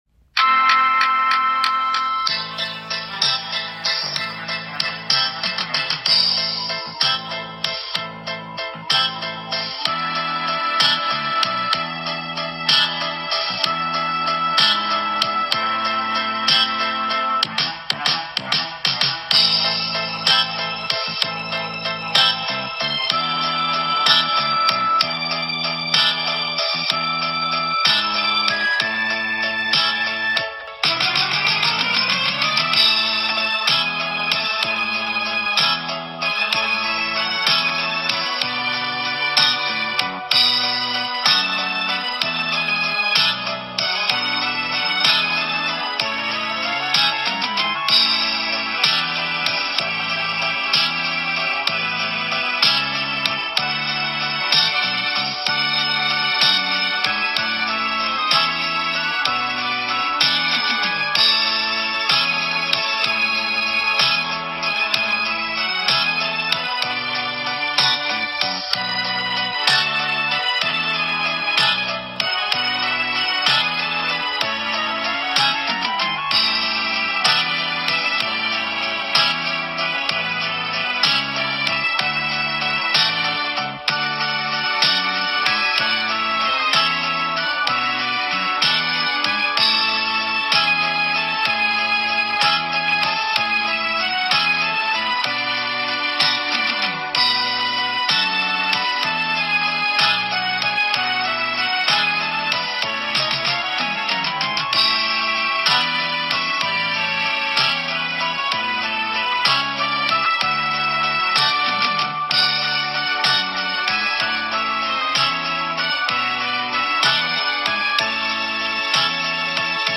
podkład: